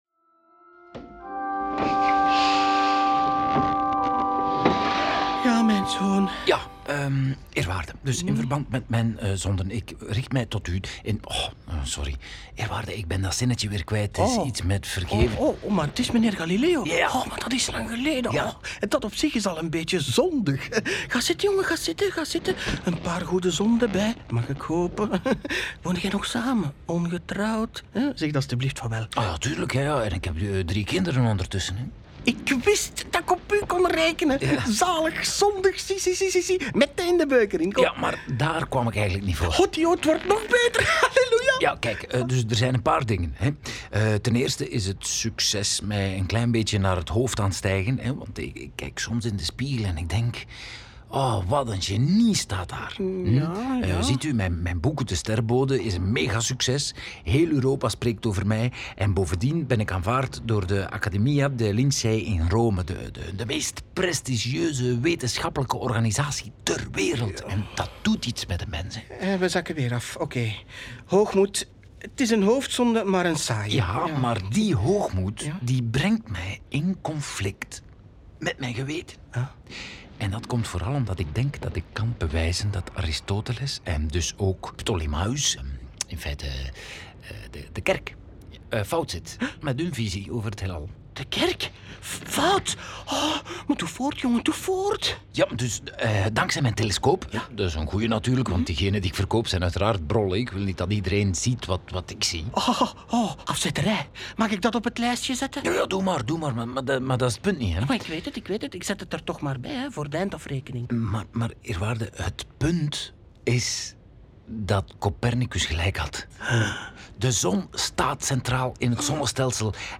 In dit experimenteel interplanetair hoorspel volgen we Galileo’s opgang en de opwinding die hij bij studenten, wetenschappers, drukkers en enthousiaste biechtvaders veroorzaakte.